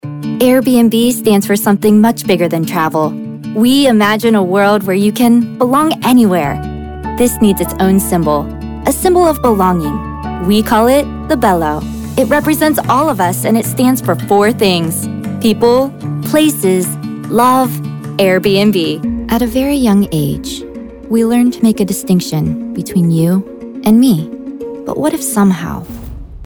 성우샘플
차분/편안